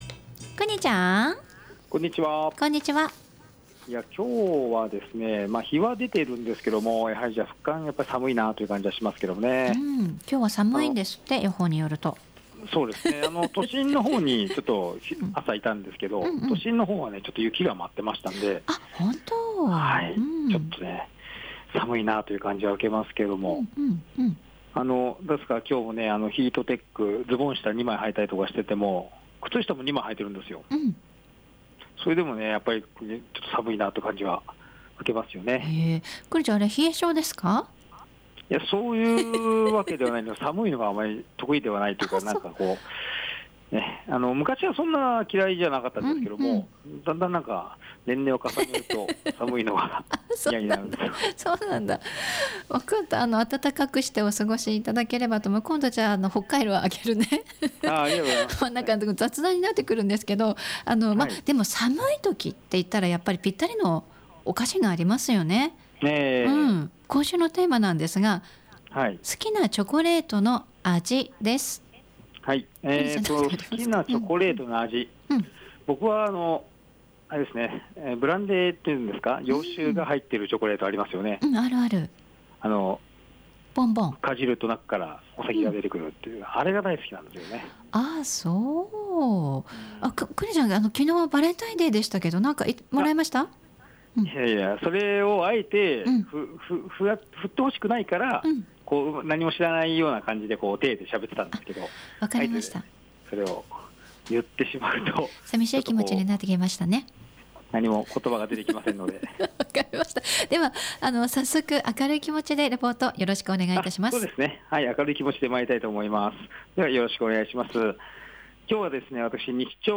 午後のカフェテラス 街角レポート
本日は自習室を利用されている方がおられましたので 事務所からお送りしました。